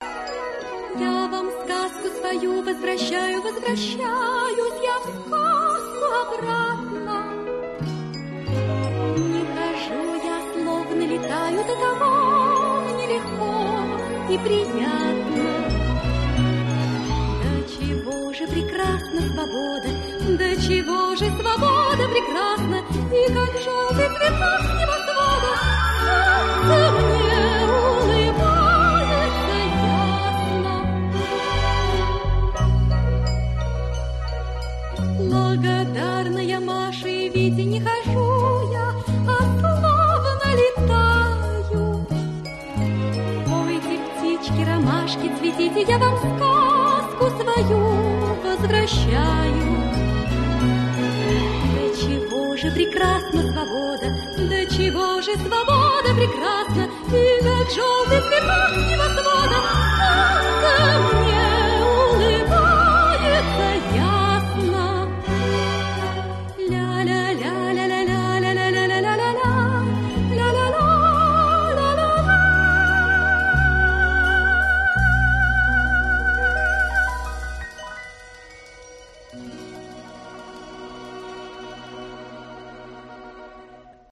композиция из советского мультфильма
Героиня красиво исполняет свою арию, под нежную мелодию.